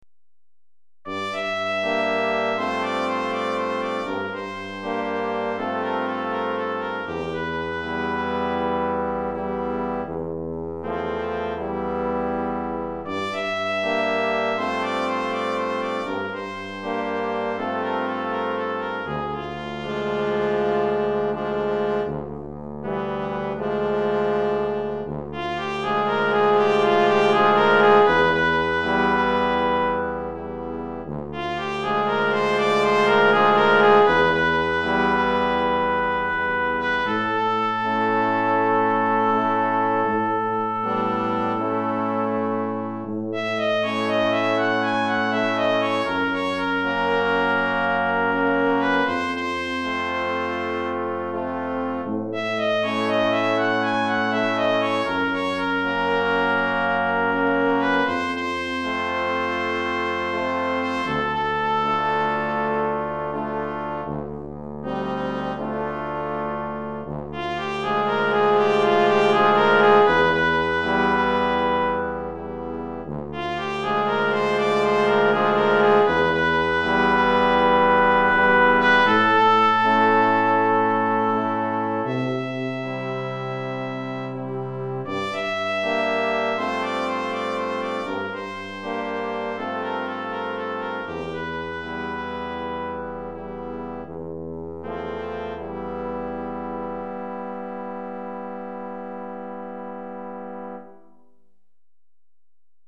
2 Trompettes Sib 1 Cor en Fa 1 Trombone 1 Tuba en